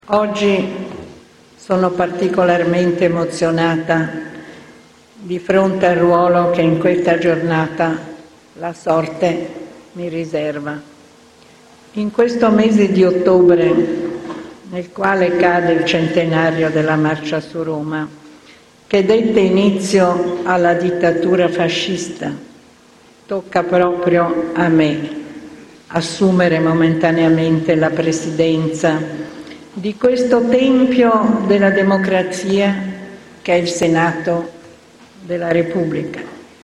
Quello di Liliana Segre al Senato è stato un discorso storico, una boccata d’ossigeno, durata troppo poco.
segre-1-senato.mp3